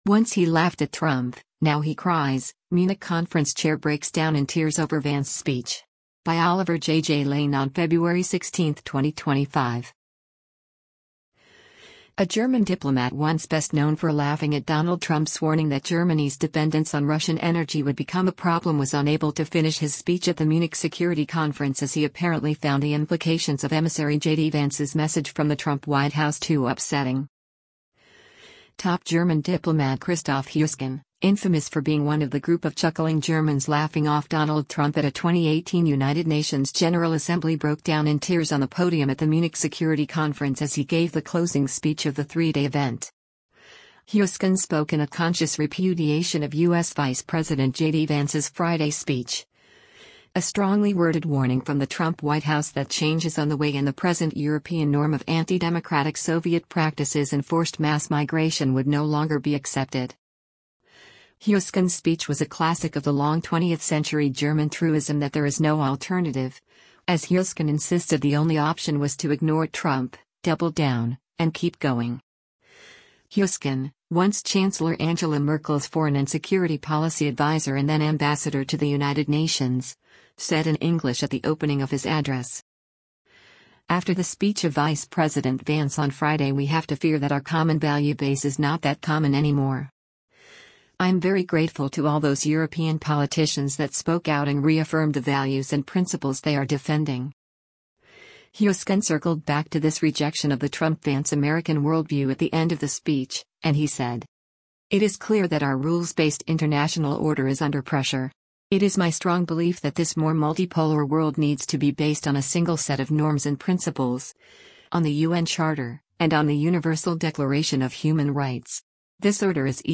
Top German diplomat Christoph Heusgen, infamous for being one of the group of chuckling Germans laughing off Donald Trump at a 2018 United Nations General Assembly broke down in tears on the podium at the Munich Security Conference as he gave the closing speech of the three-day event.
At this point, the giant of the German diplomatic community broke down in tears.